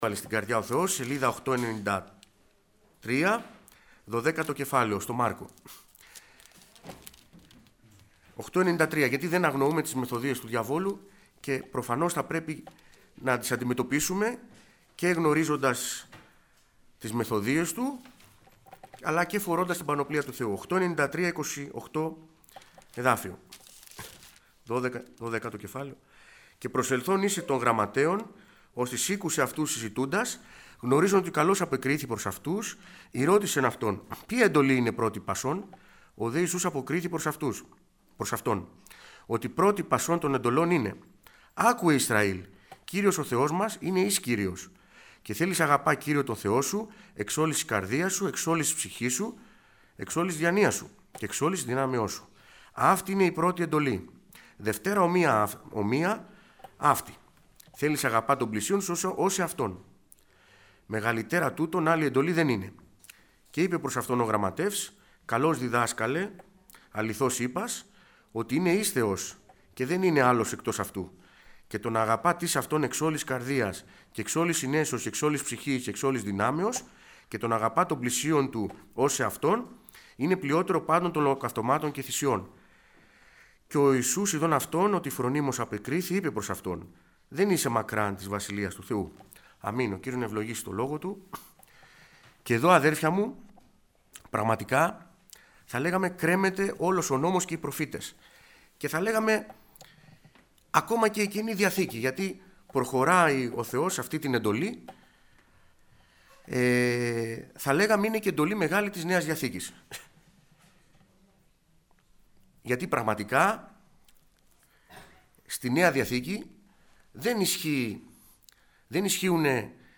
Κηρυγμα Ευαγγελιου